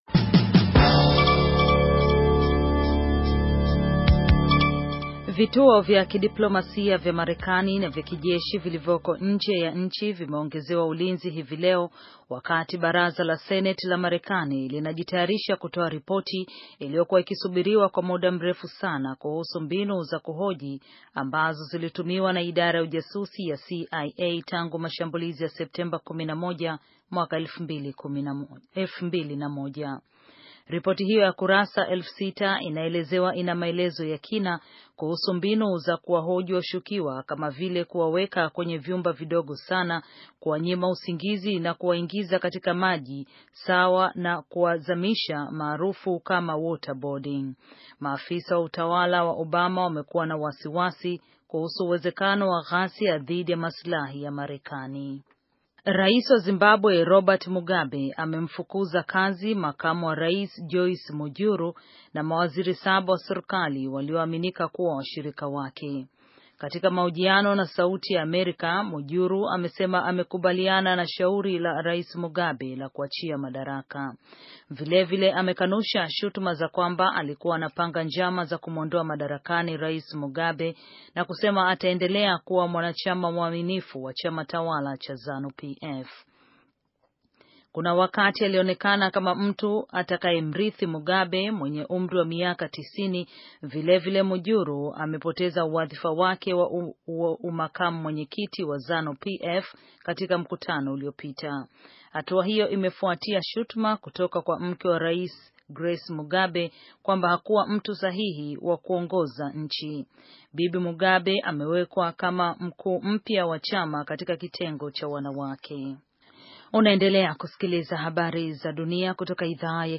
Taarifa ya habari - 6:13